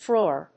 • : -ɔː(ɹ)